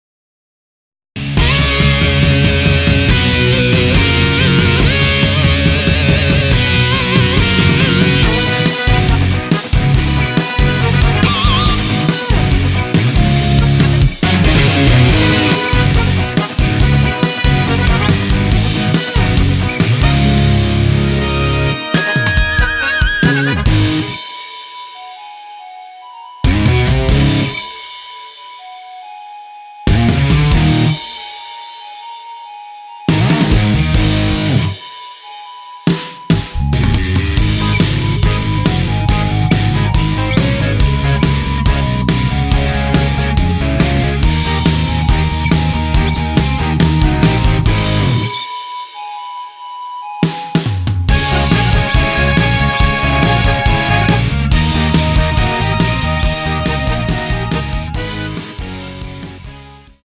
원키 멜로디 포함된 MR입니다.
Ab
앞부분30초, 뒷부분30초씩 편집해서 올려 드리고 있습니다.
중간에 음이 끈어지고 다시 나오는 이유는